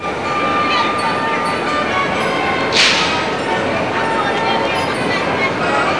1 channel
00004_Sound_Carnival.mp3